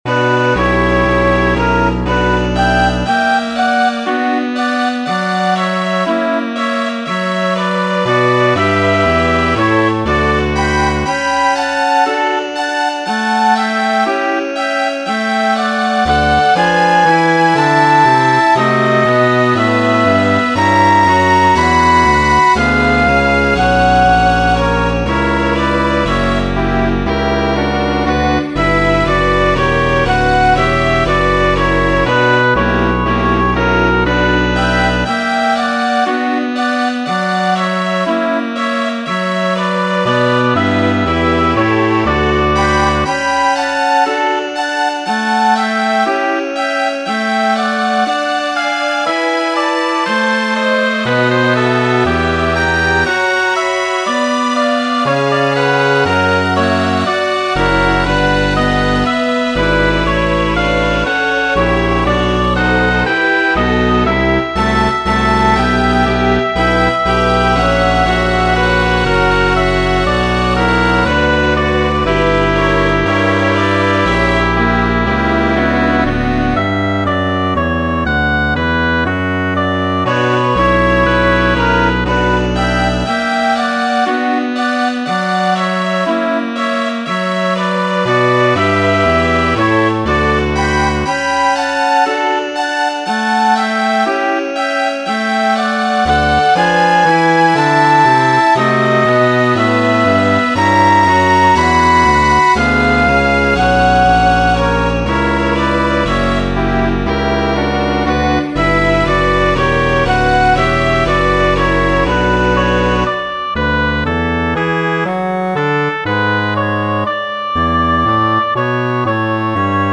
Sangu nur (Blute Nur) kanto por soprano de la Pasio laŭ Mateo